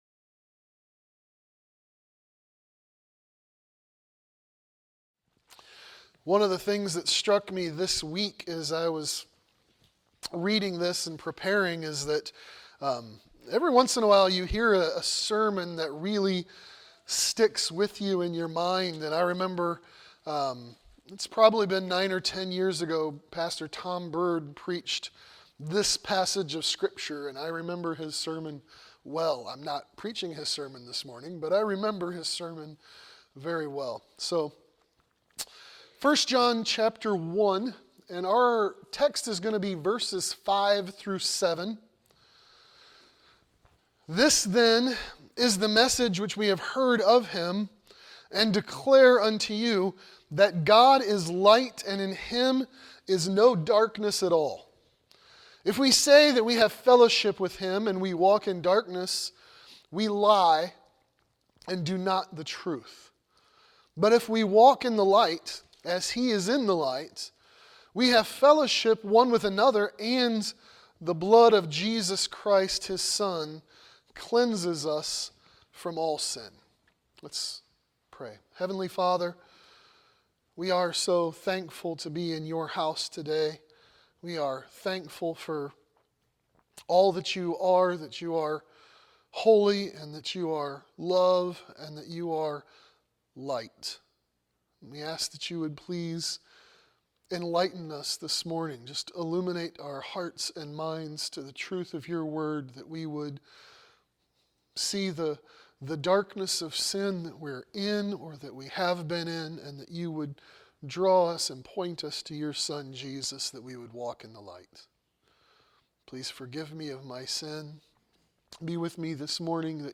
Walking In The Light | SermonAudio Broadcaster is Live View the Live Stream Share this sermon Disabled by adblocker Copy URL Copied!